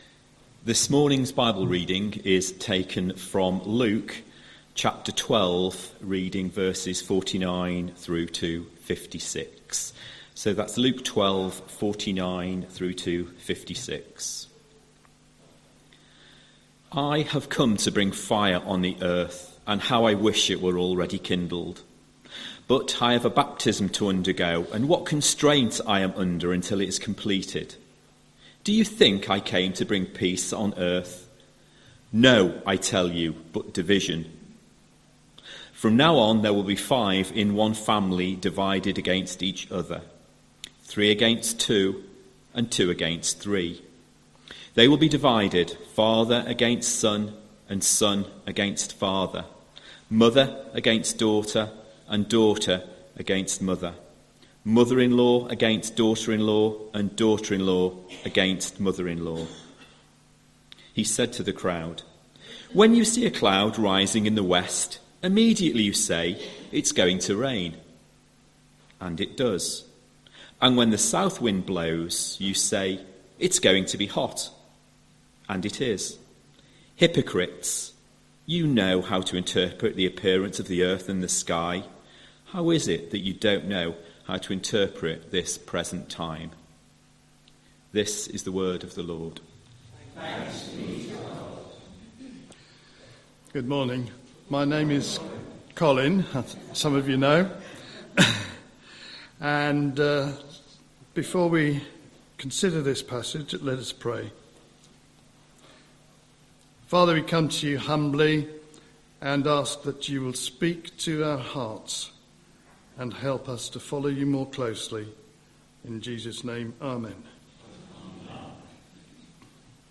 17th August 2025 Sunday Reading and Talk - St Luke's